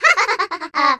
ahaha.wav